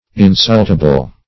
Search Result for " insultable" : The Collaborative International Dictionary of English v.0.48: Insultable \In*sult"a*ble\, a. Capable of being insulted or affronted.